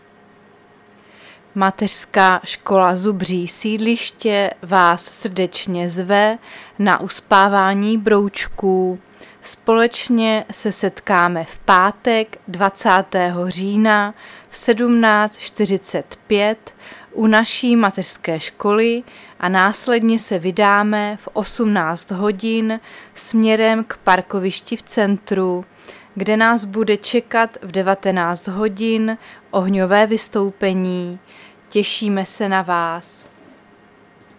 Záznam hlášení místního rozhlasu 16.10.2023
Zařazení: Rozhlas